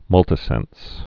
(mŭltĭ-sĕns)